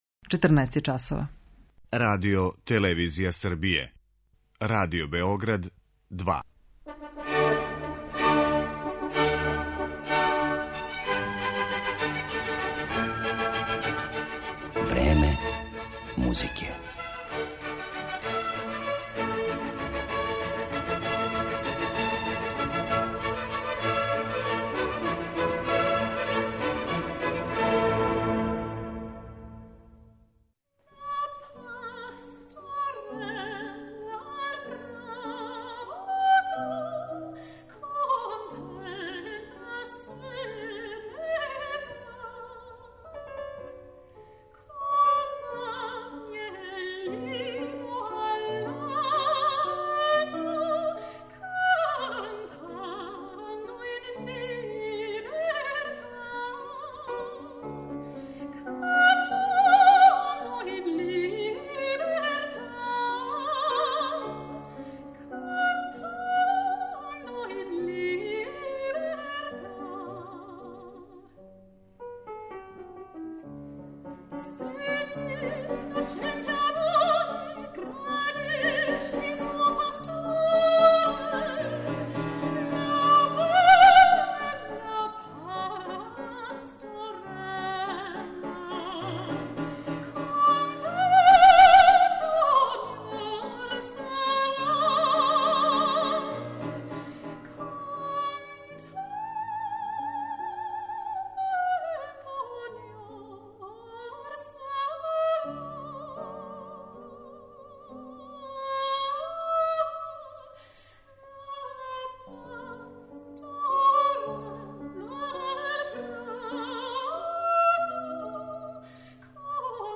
Немачки лид
Композиције Августа Хербинга, Карла Филипа Емануела Баха, Фридриха Флајшера, Кристијана Волфа и Волфганга Амадеуса Моцарта изводиће једна од најатрактивнијих вокалних солисткиња данашњице - британски сопран Керолајн Семпсон.